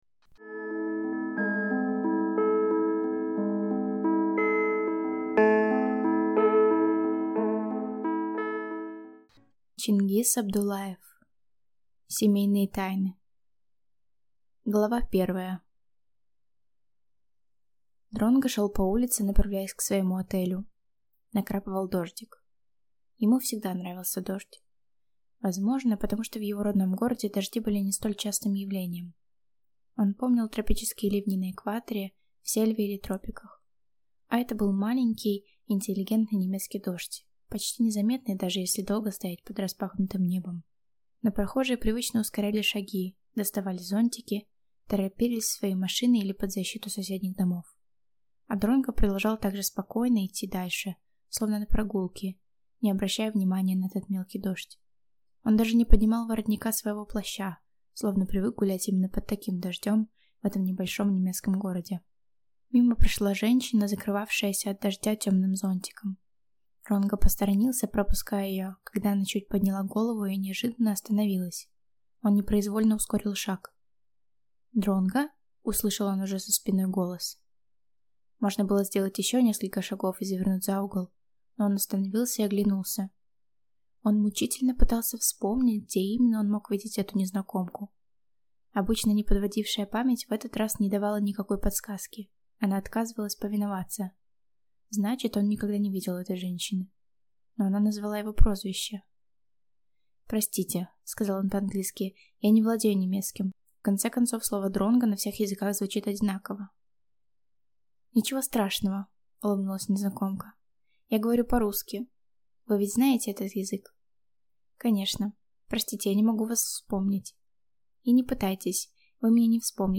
Аудиокнига Семейные тайны | Библиотека аудиокниг